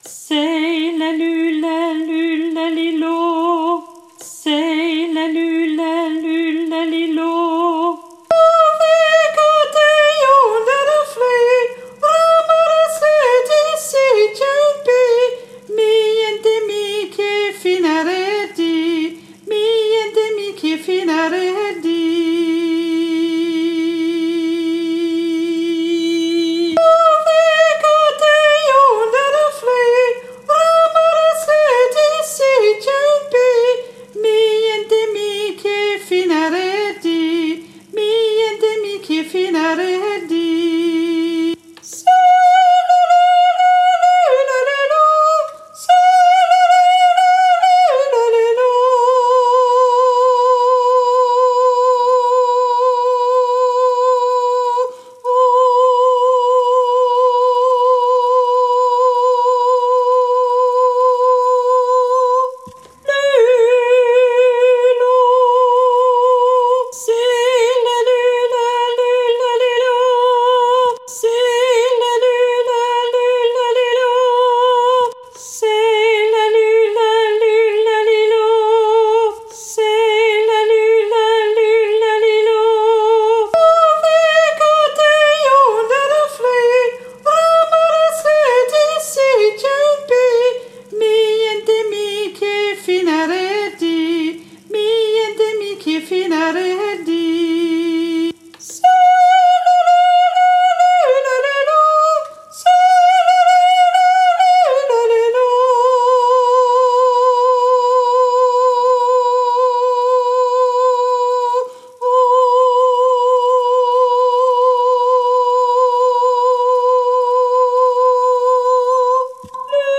Tenor 2